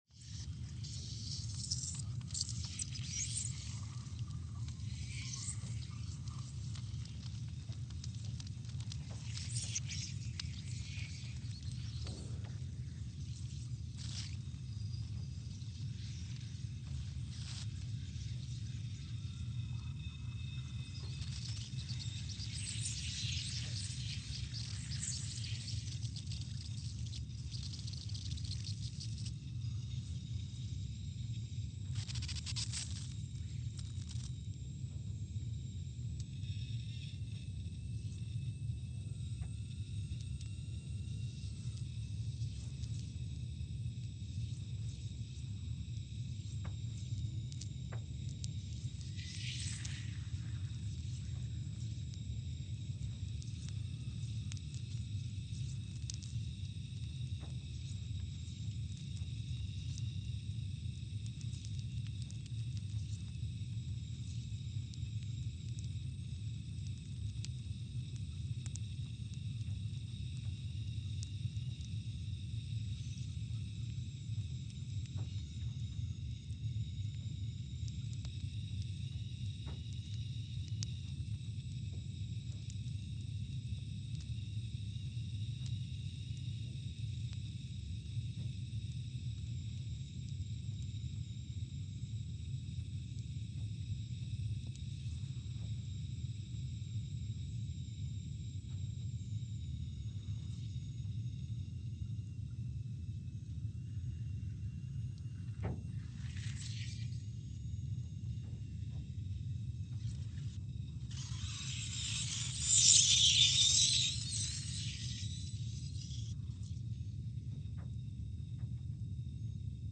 Scott Base, Antarctica (seismic) archived on October 18, 2019
Station : SBA (network: IRIS/USGS) at Scott Base, Antarctica
Speedup : ×500 (transposed up about 9 octaves)
Loop duration (audio) : 05:45 (stereo)